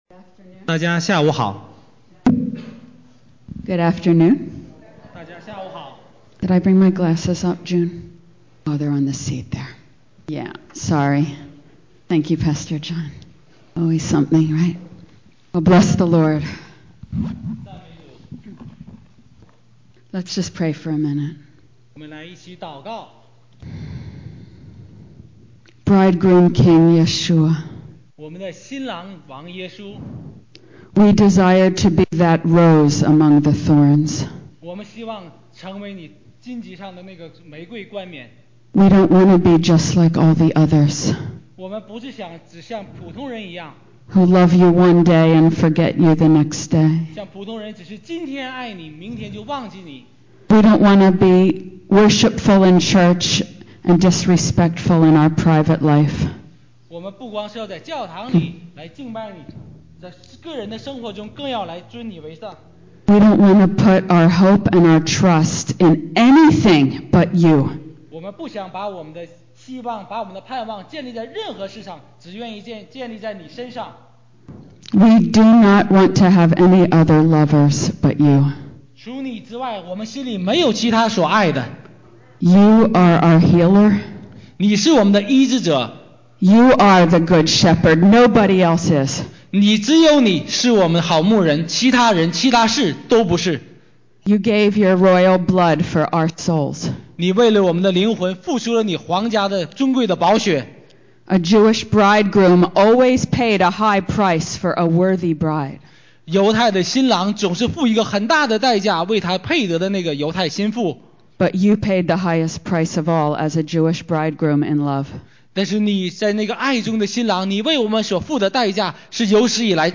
2017温哥华国际新妇特会(4)